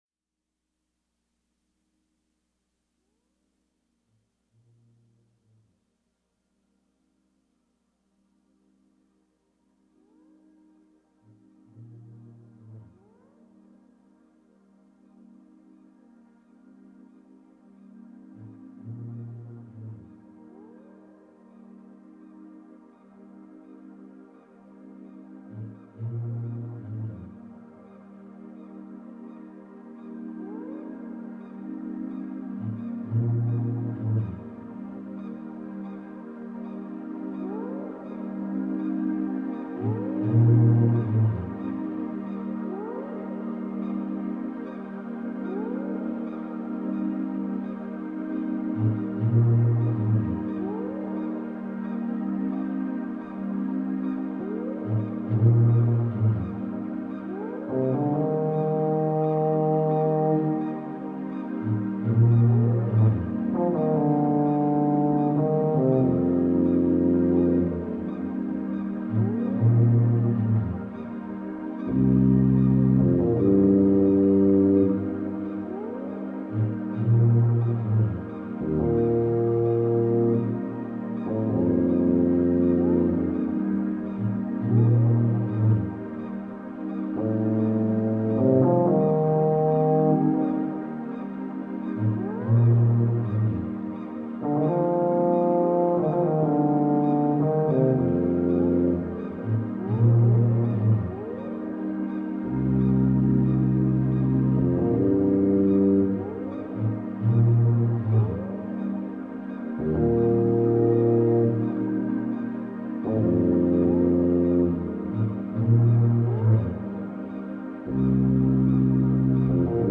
Soundtrack, Folk Rock, Instrumental, Jazz Fusion